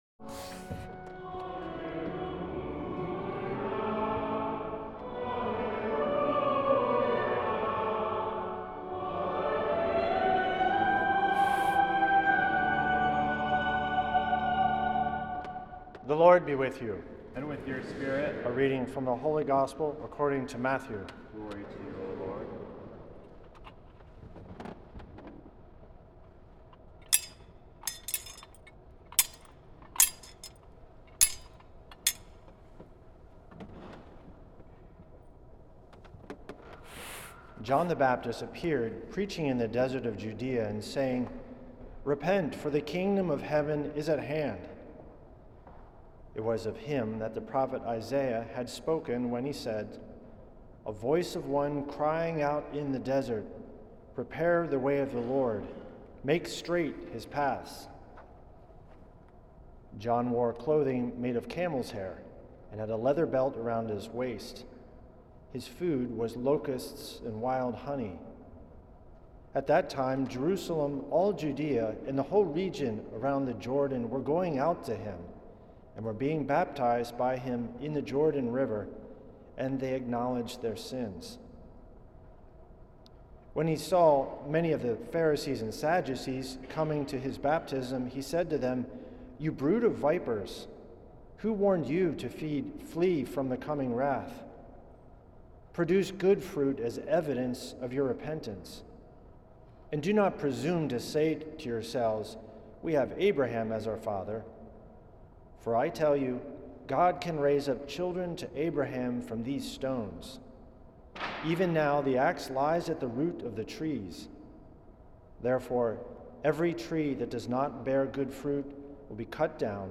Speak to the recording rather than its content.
at St. Patrick’s Old Cathedral in NYC